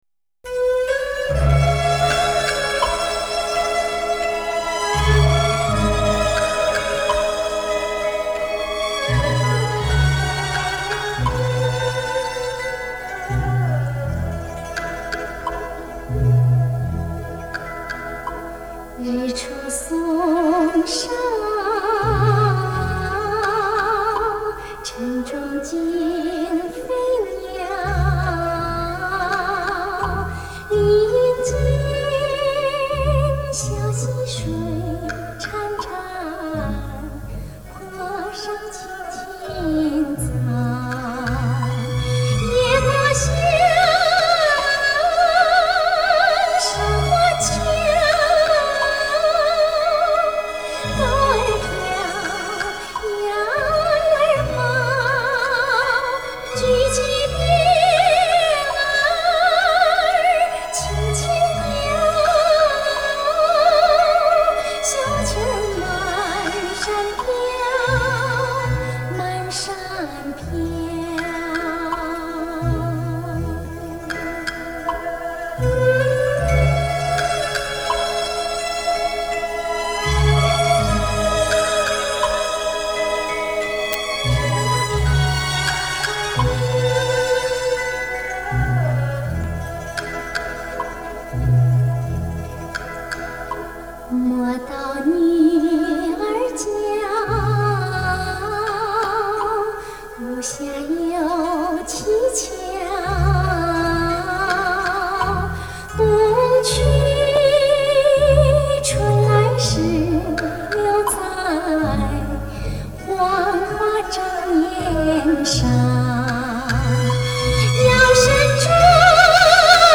演唱者的迷人声线或甜美而不失骄媚，或自然而更显醇厚，共您回想当年的点滴真情，伴您在缕缕缠绵中回味如歌往事，感悟百味人生。